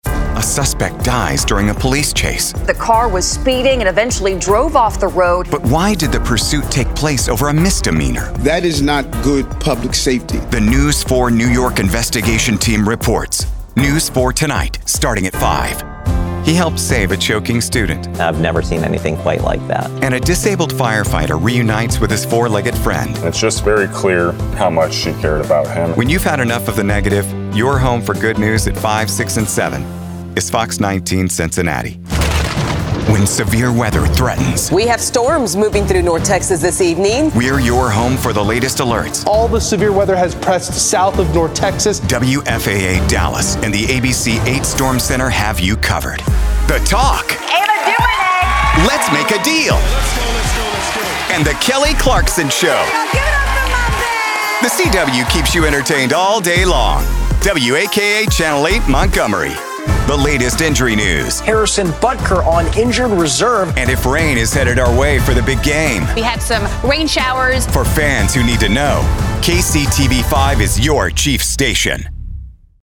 Dynamic, Friendly, Engaging
All-American Voice